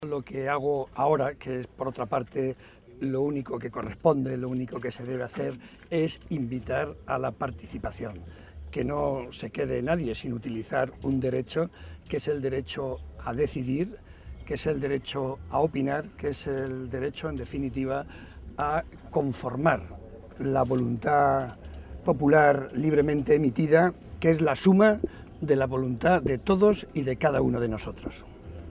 Barreda, que hizo estas declaraciones en Ciudad Real, tras asistir a depositar su voto, señaló que “hoy puede ser un gran día si hay mucha participación” pues, en su opinión, “eso es lo que corresponde al día de hoy”.